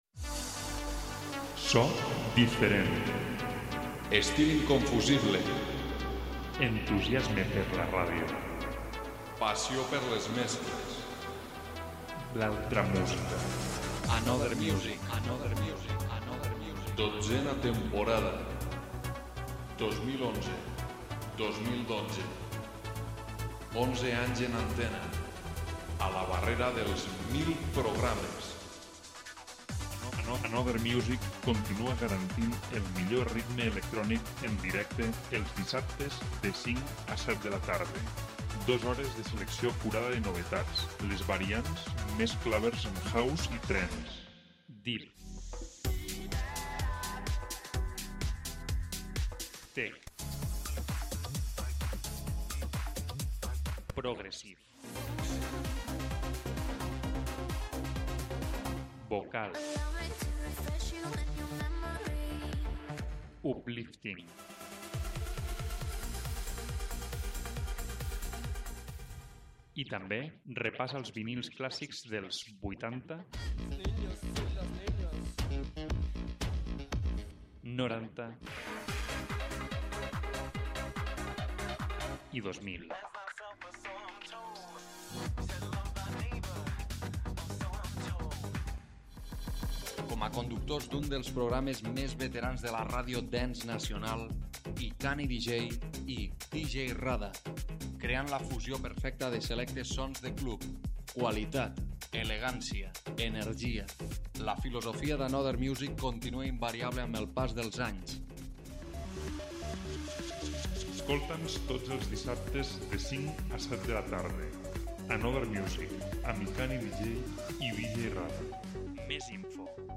recomanació de nous sons tech, deep house i trance.